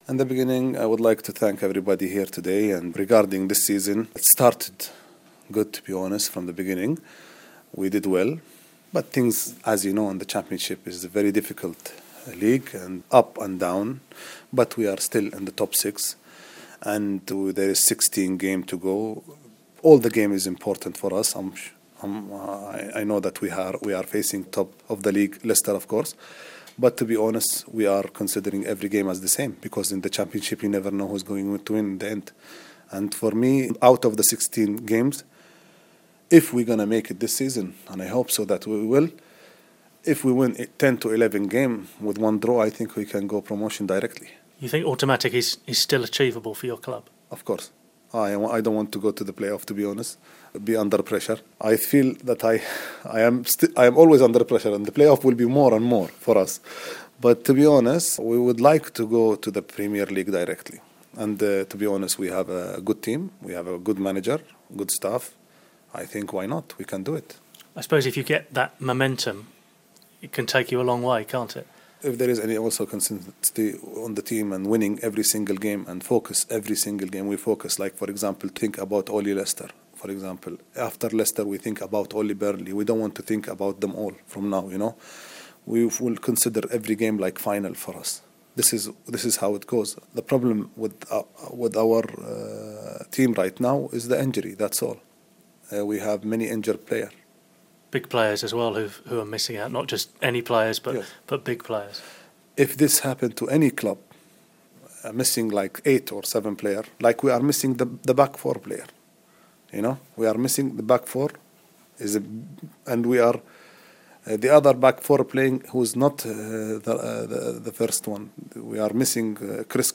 Fawaz Al Hasawi interview with BBC Radio Nottingham